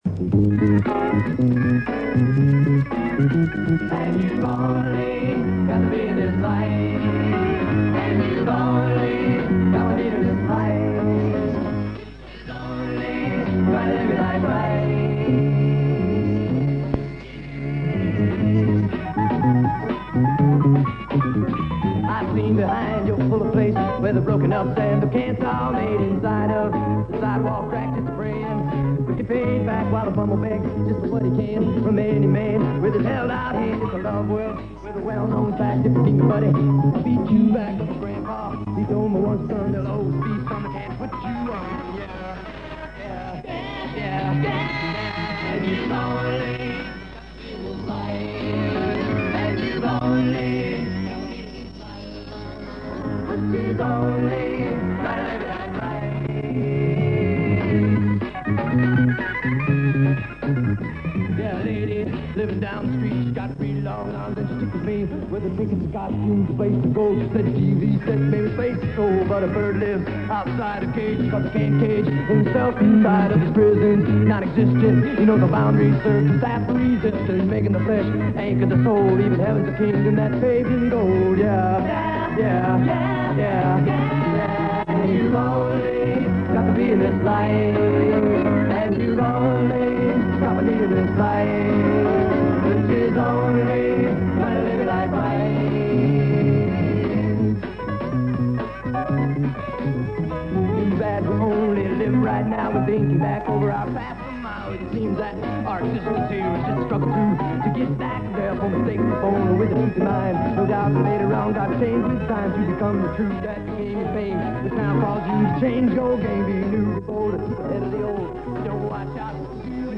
почто не написал,что rhythm&blues - rock